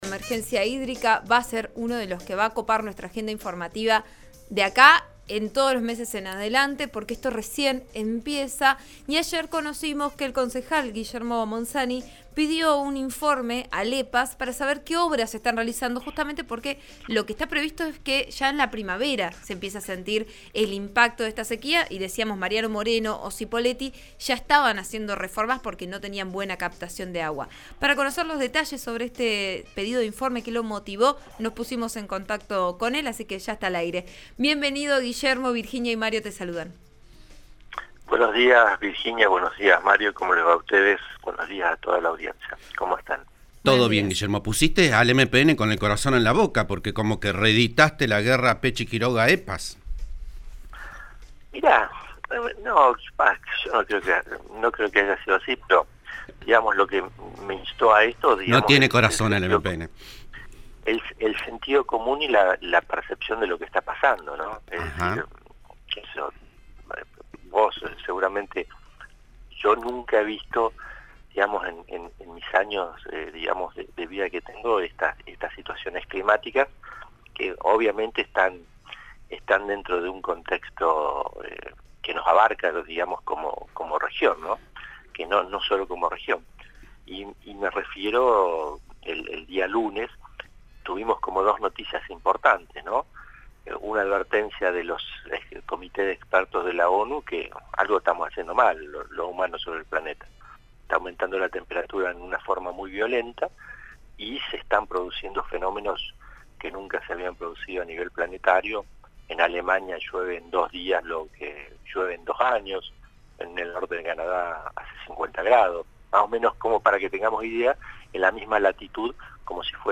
Guillermo Monzani, concejal por Juntos por el Cambio – UCR, habló en Vos A Diario (RN RADIO 89.3) al respecto.